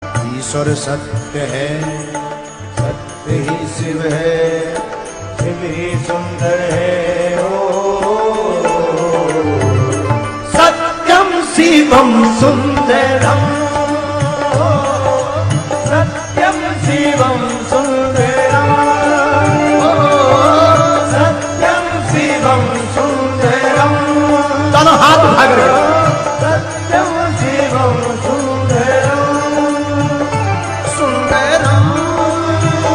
tamil ringtonebhajan ringtonebhakti ringtoneshiva ringtone
best flute ringtone download